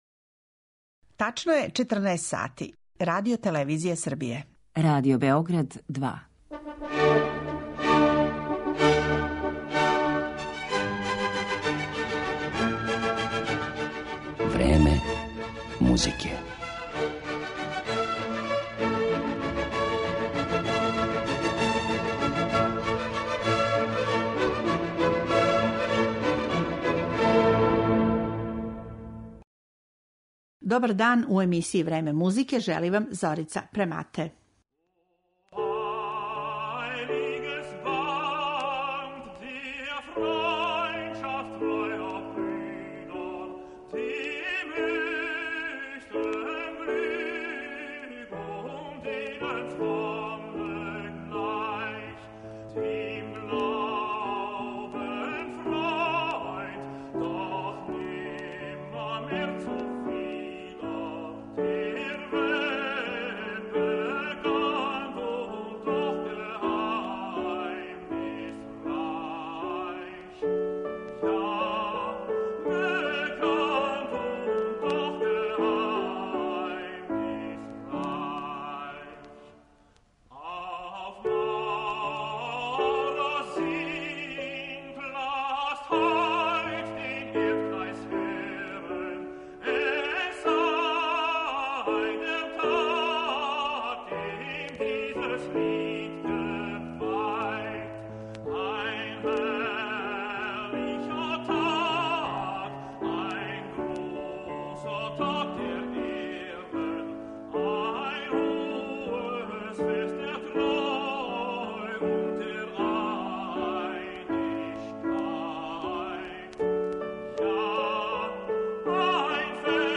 Почев од 1785. године, компоновао је углавном песме уз камерни ансамбл или кантате са темама и стиховима који су одговарали масонским обредима, па ћемо емитовати управо избор из овог сегмента Моцартовог опуса.